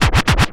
scratch07.wav